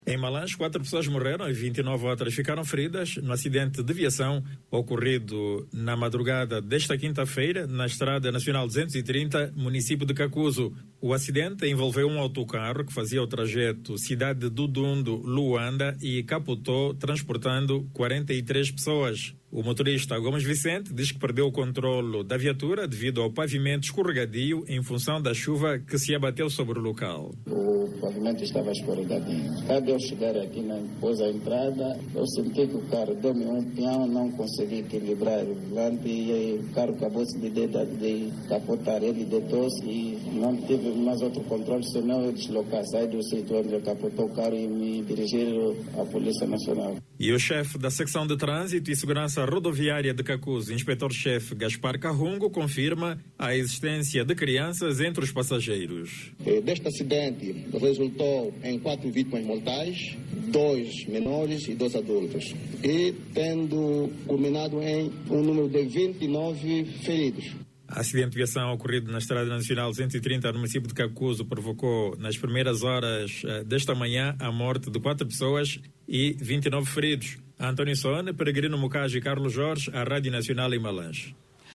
Um acidente de viação ocorrido na madrugada de quinta-feira(28), provocou a morte de quatro pessoas e o ferimento de 29 outras. Tudo aconteceu quando um autocarro que transportava mais de 40 passageiros capotou, depois que perdeu o controlo. Clique no áudio abaixo e ouça a reportagem